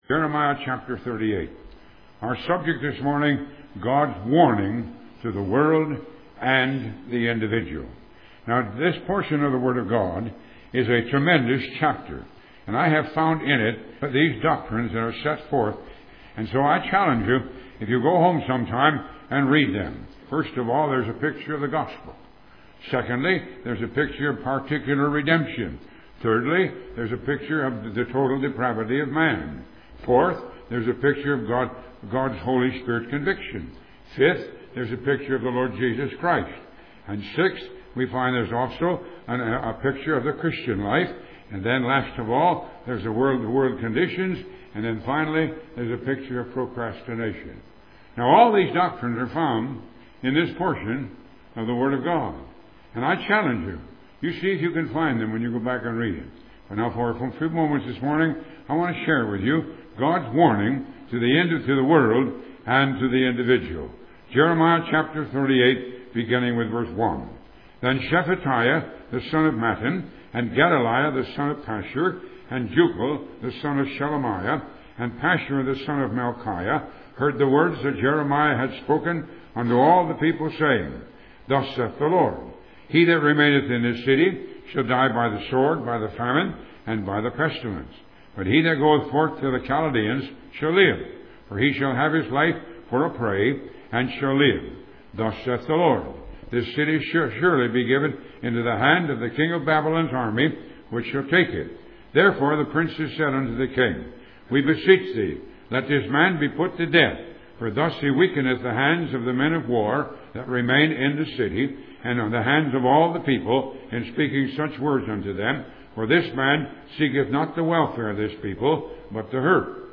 Upon his passing, the Ministry has continued the radio broadcast on some radio stations and through various social media sites.